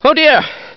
Download Half Life Oh Dear sound effect for free.